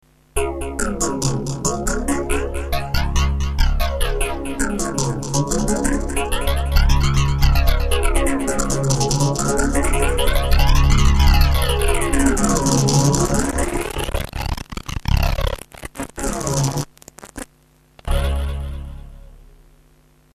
All done live demonstrating how easy (and fun) it is to get some great riffs.
Arp till you drop...increasing the arp speed until the midi bus is packed out and the synth boms out.